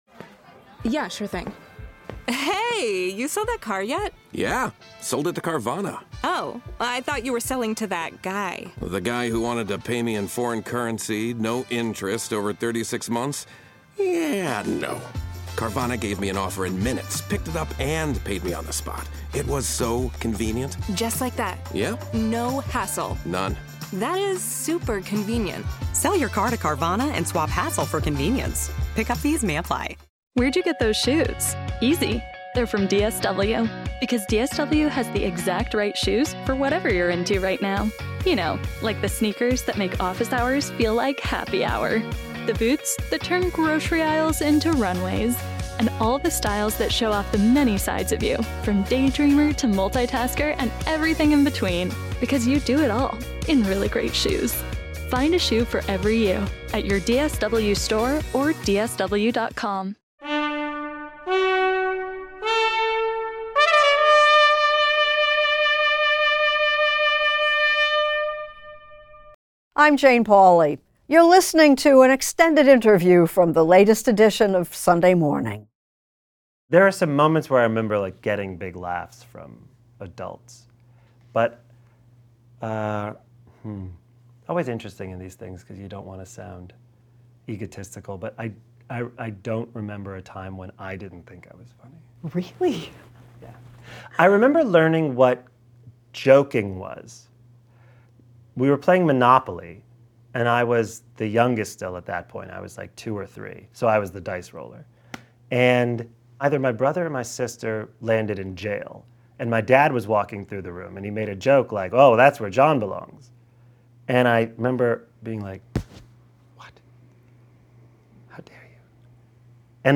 Extended Interview: John Mulaney on his Standup Persona
Standup comedian John Mulaney talks with correspondent Tracy Smith about his earliest experience learning about jokes; developing his on-stage persona (and why it involves a suit); why he's happiest writing for other people; and how he approaches his sobriety after having gone through rehab and becoming a father of two children.